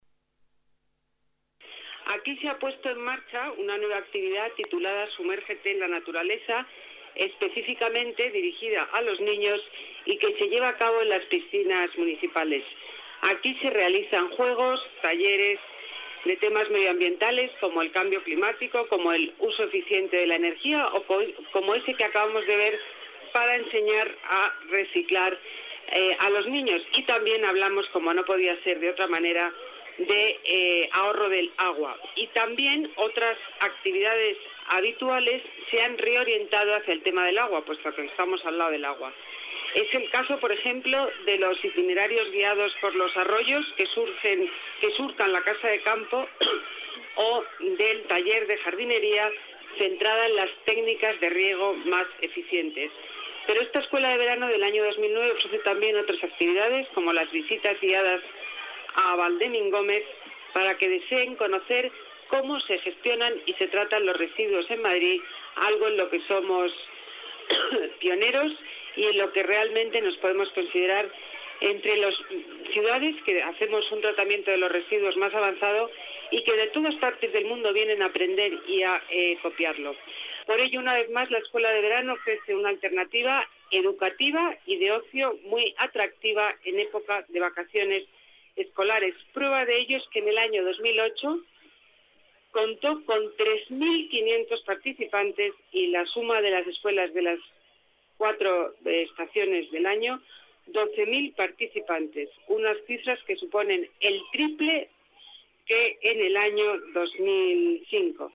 Nueva ventana:Declaraciones delegada Medio Ambiente, Ana Botella: éxito Escuela Verano